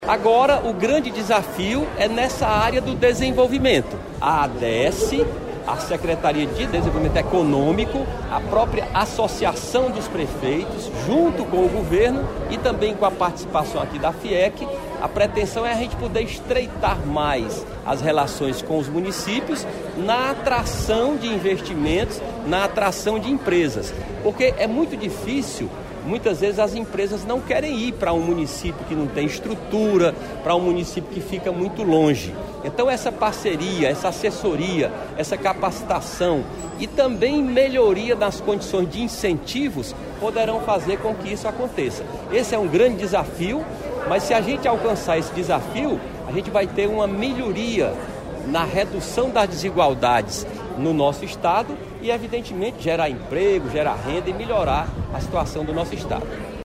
Governo do Ceará reúne prefeituras em seminário para atração de investimentos
Nelson Martins, secretário-chefe da Casa Civil, destaca que o grande desafio é na área do desenvolvimento.